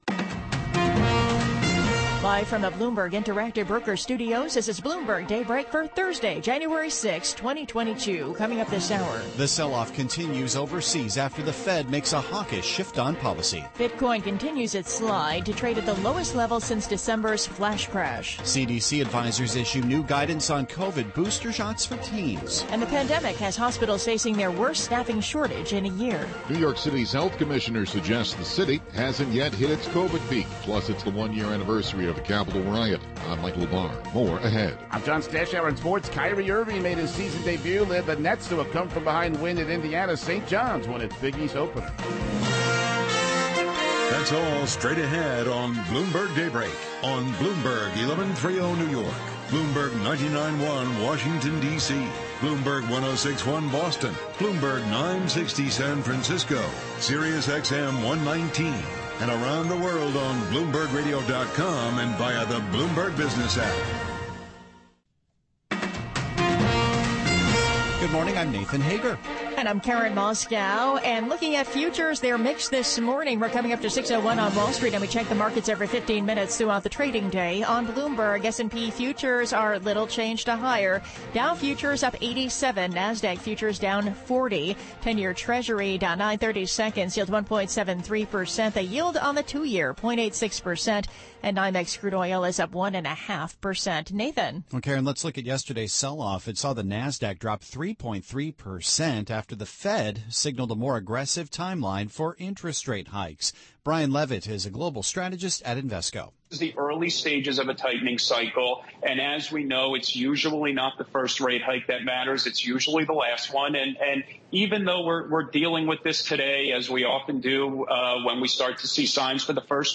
Bloomberg Daybreak: January 6, 2022 - Hour 2 (Radio) 42:50 Share